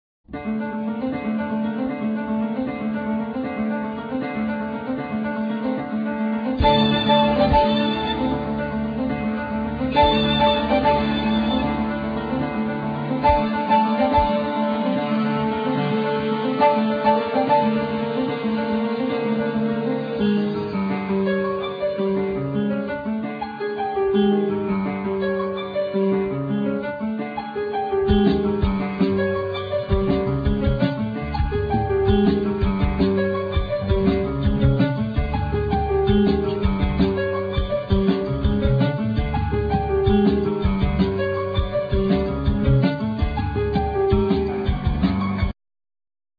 Piano,Keyboards,Vocals
Double bass
Violin,Viola
Bassoon
Cello